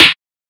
BWB WAV R US SNARE (7).wav